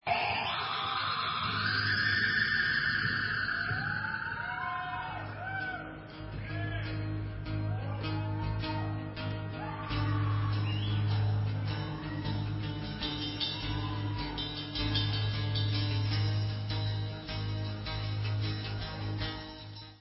live 2001